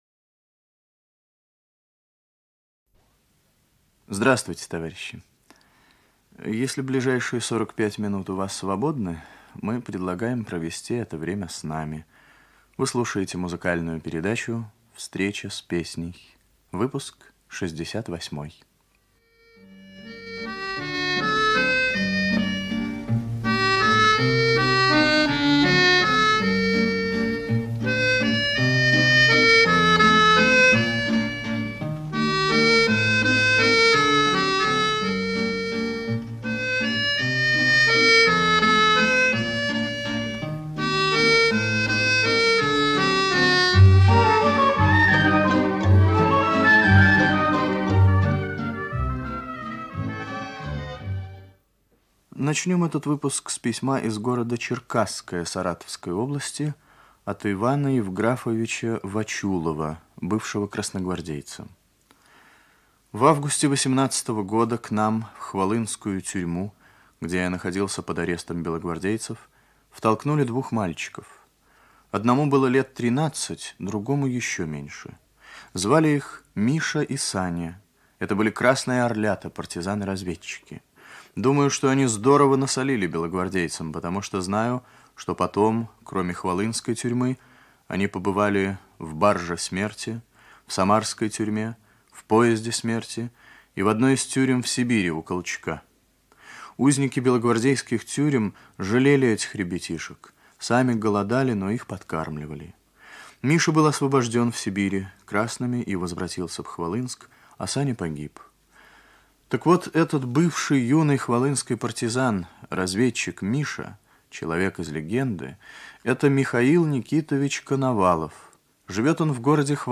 Радиопередача "Встреча с песней" Выпуск 68
Ведущий - автор, Виктор Татарский.
1 Русская народная песня - “По пыльной дороге”. Краснознаменный им. А. В. Александрова Ансамбль песни и пляски Советской Армии.